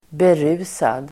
Uttal: [ber'u:sad]